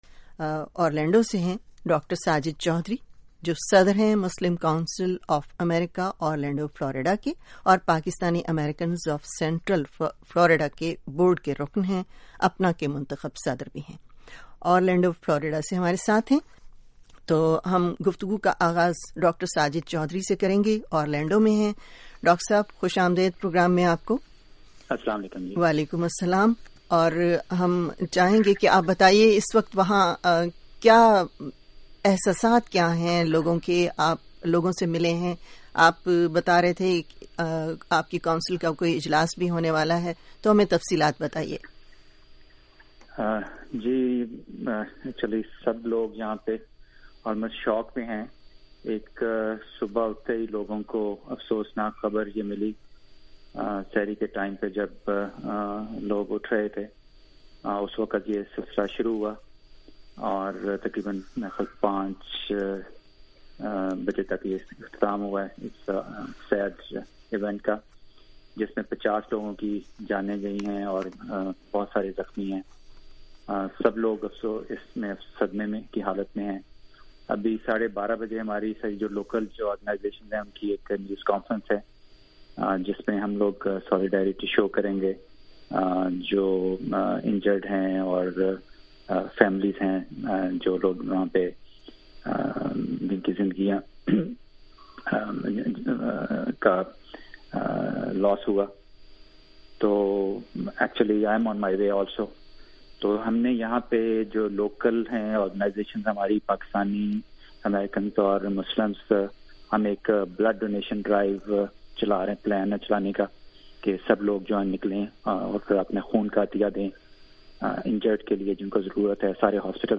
'وائس آف امریکہ' اردو ریڈیو کے پروگرام "خط آپ کے"